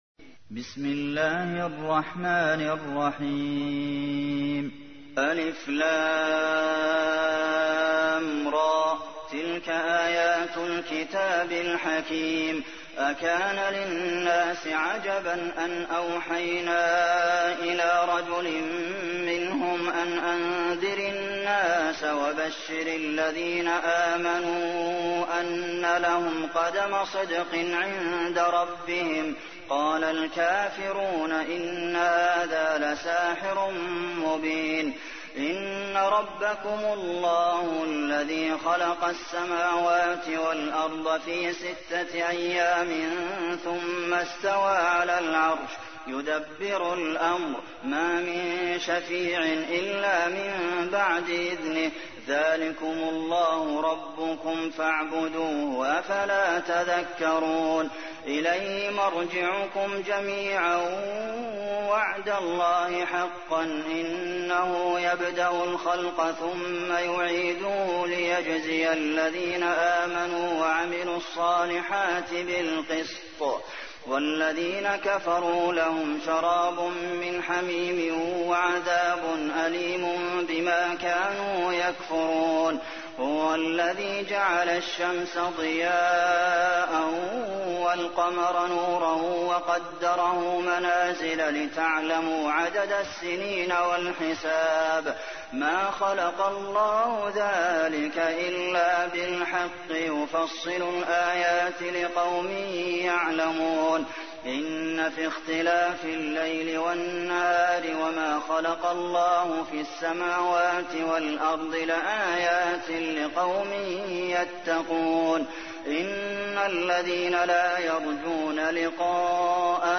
تحميل : 10. سورة يونس / القارئ عبد المحسن قاسم / القرآن الكريم / موقع يا حسين